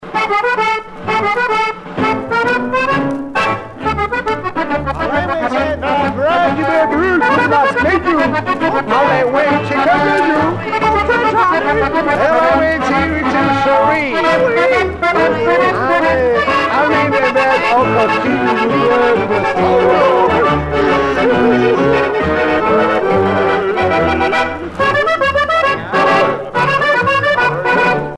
danse : one-step
Pièce musicale éditée